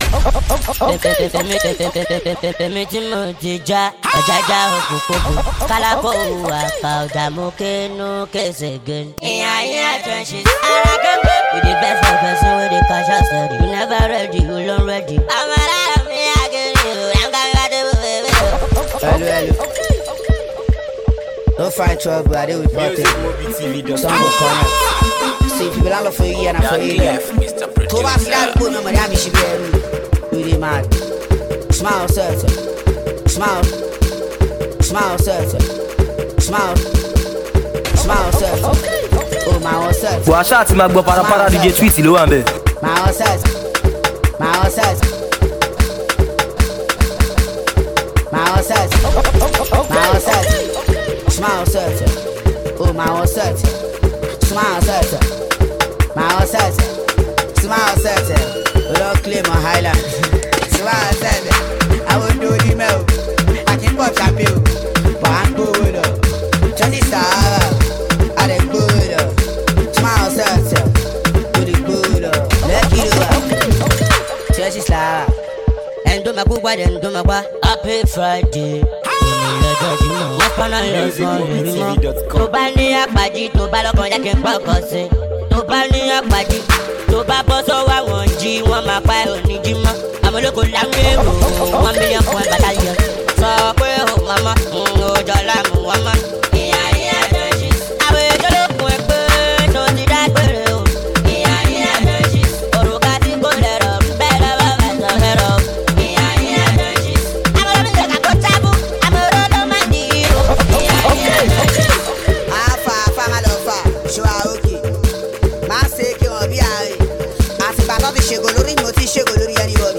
street Mixtape